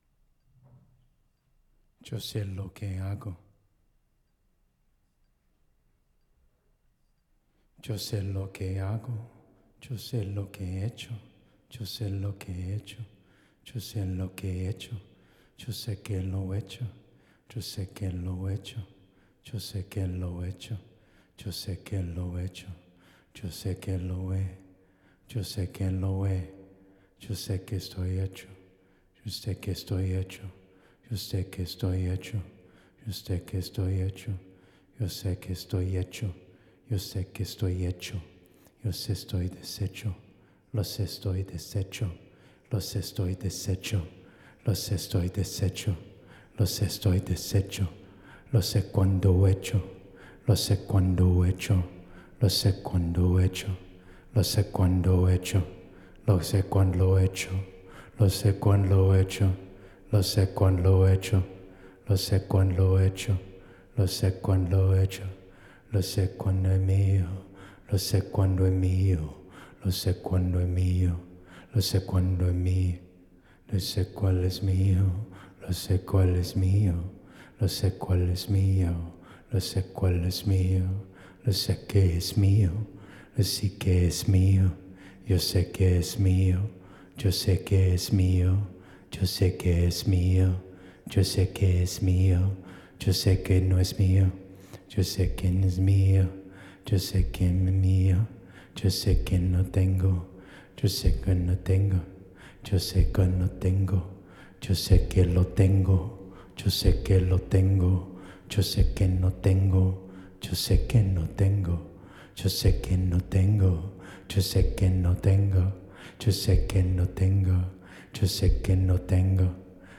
theatrical chamber music